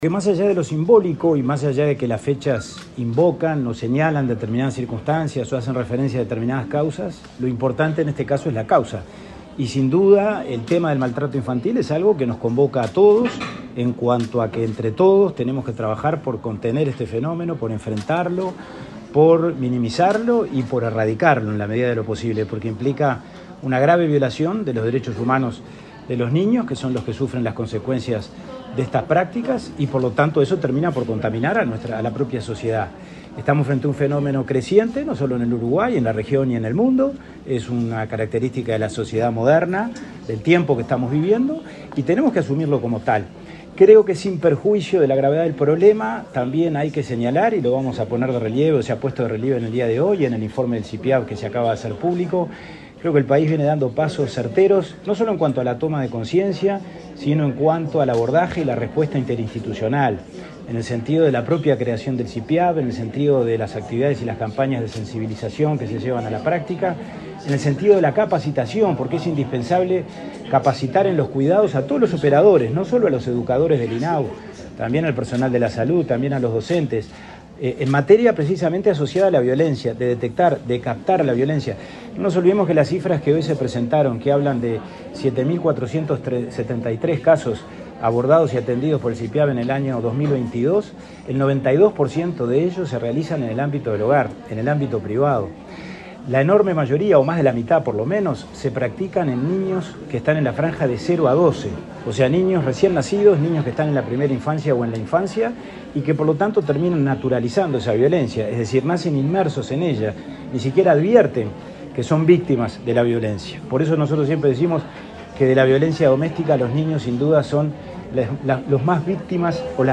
Declaraciones del presidente de INAU, Pablo Abdala
Declaraciones del presidente de INAU, Pablo Abdala 25/04/2023 Compartir Facebook X Copiar enlace WhatsApp LinkedIn En el marco del Día Internacional de Lucha contra el Maltrato y el Abuso Sexual hacia Niñas, Niños y Adolescentes, el presidente del Instituto Nacional del Niño y el Adolescente (INAU), Pablo Abdala, dialogó con la prensa antes de la presentación del informe anual de gestión de 2022 sobre esta temática.